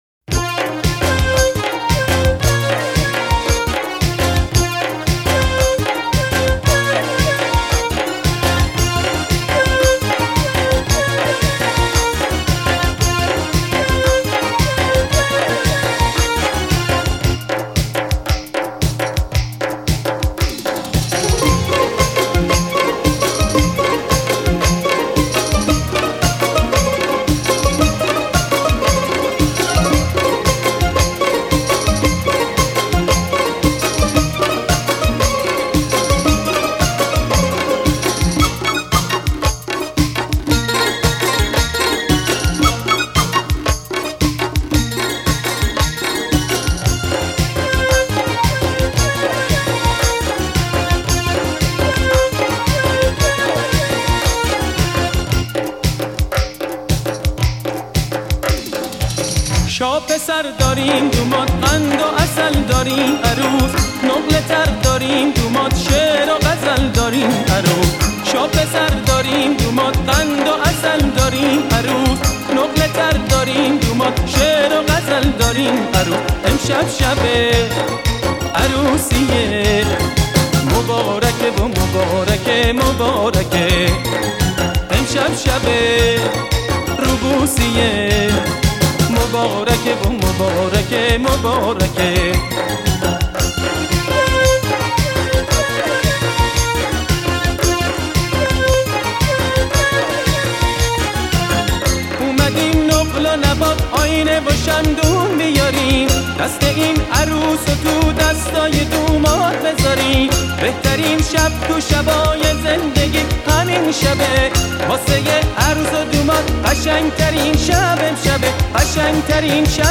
ژانر: پاپ
توضیحات: ریمیکس شاد ترانه های قدیمی و خاطره انگیز